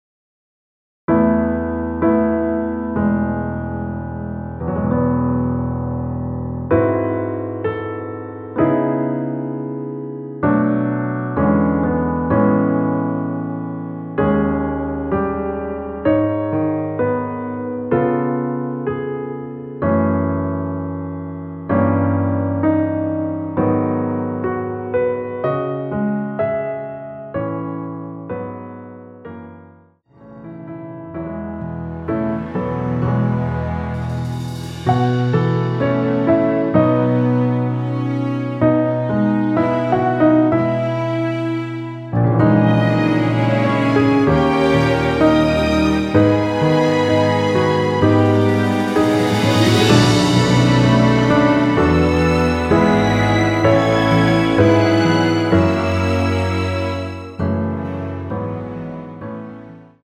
전주 없이 시작하는 곡이라 노래하기 편하게 전주 1마디 만들어 놓았습니다.(미리듣기 확인)
원키에서(-2)내린 MR입니다.
앞부분30초, 뒷부분30초씩 편집해서 올려 드리고 있습니다.